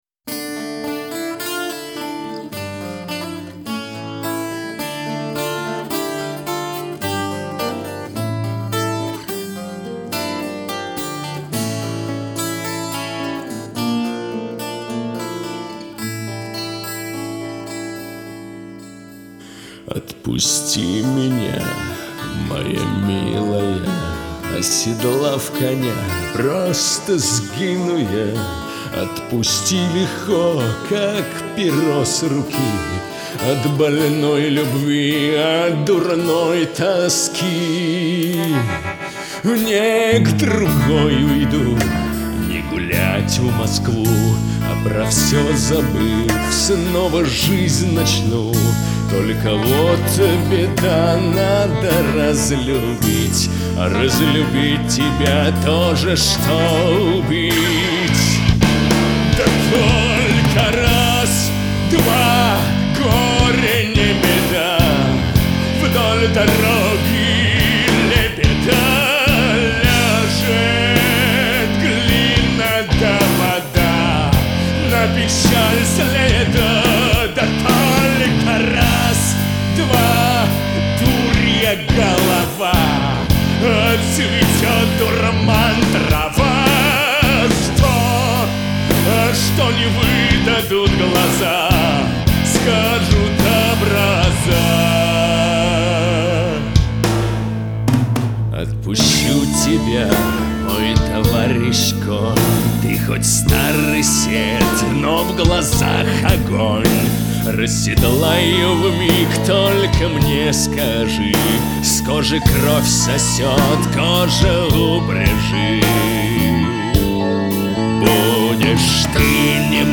гусли, бэк-вокал.